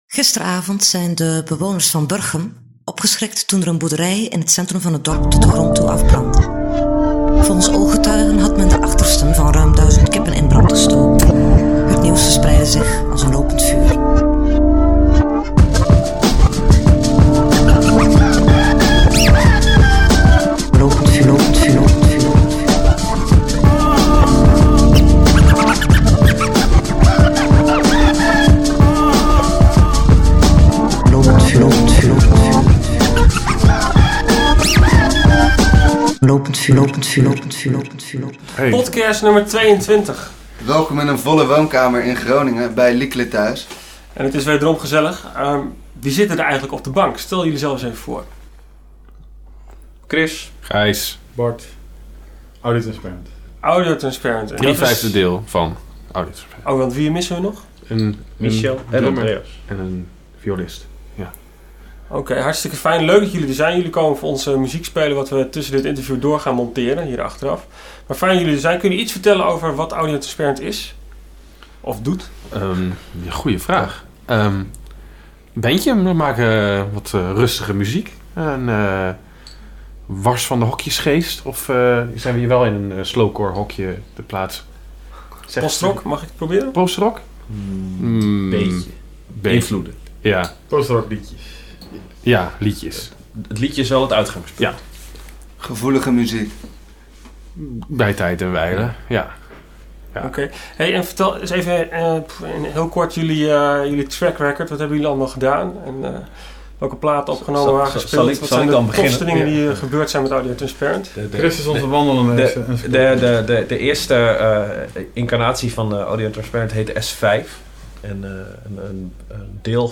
In deze editie hebben we drie heren van Audiotransparent op bezoek. Ze vertellen niet alleen een mooi verhaal over hun muziek en de manier waarop ze deze de wereld in slingeren. Ze trakteren ons ook op een fenomenaal optreden.